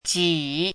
注音： ㄐㄧˇ
ji3.mp3